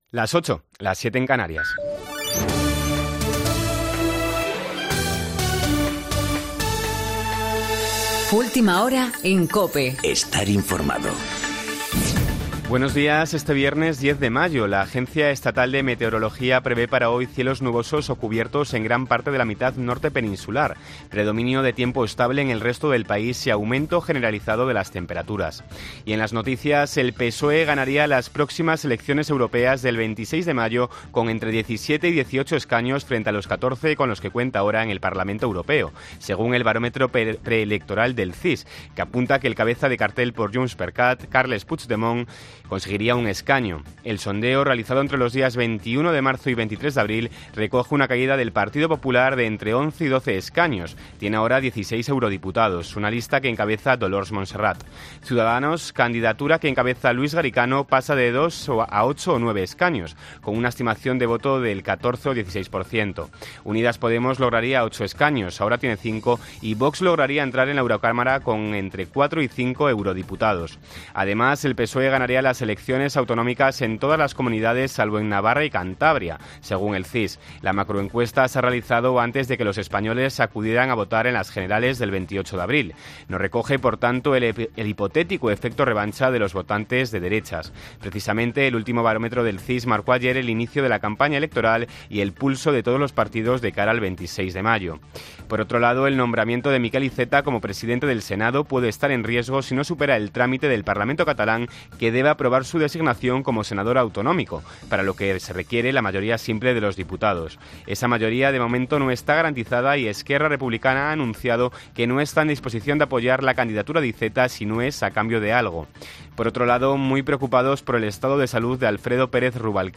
Boletín de noticias COPE del 10 de mayo de 2019 a las 08.00